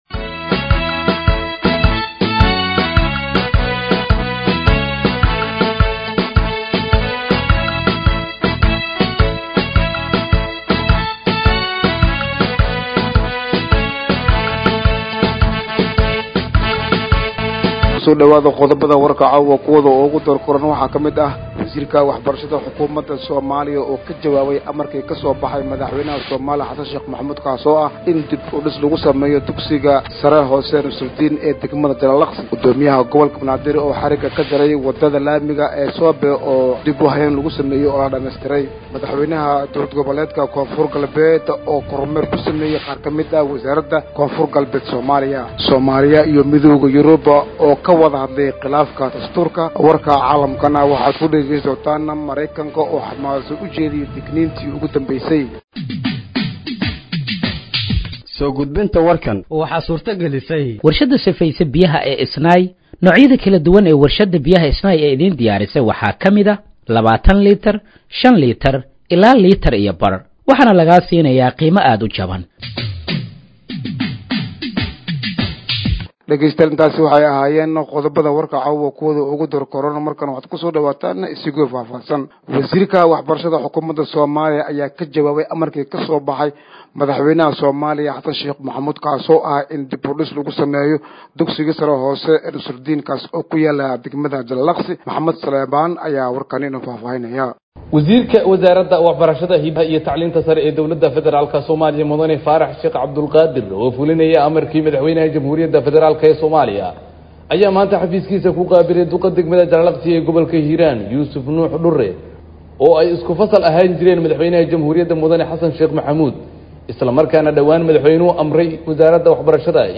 Dhageeyso Warka Habeenimo ee Radiojowhar 08/09/2025